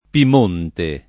[ pim 1 nte ]